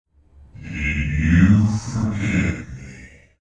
This page was titled "Forget" and contained the audio "ForgetMe.mp3", which featured a deep voice asking "Did you forget me?".